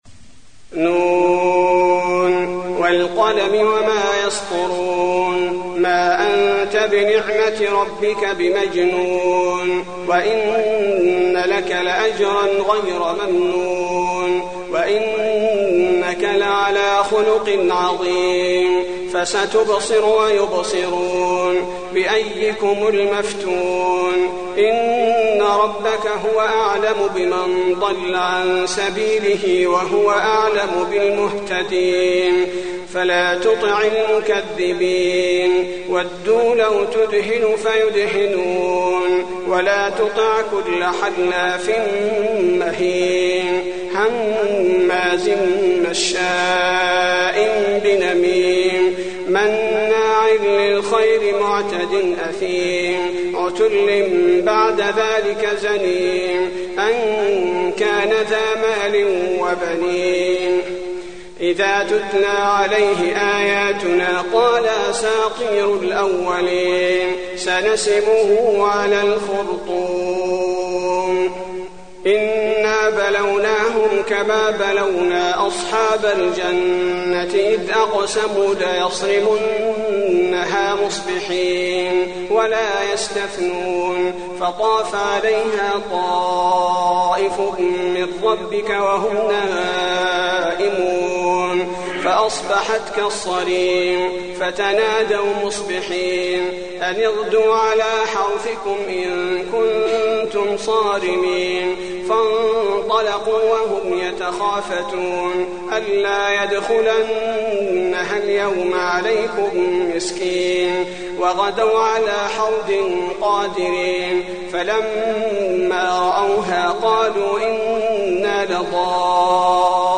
المكان: المسجد النبوي القلم